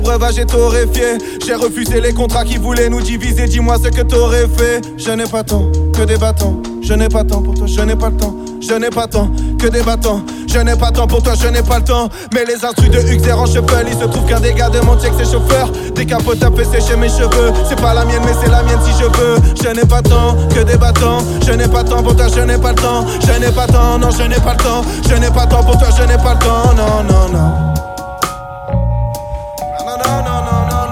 Rap francophone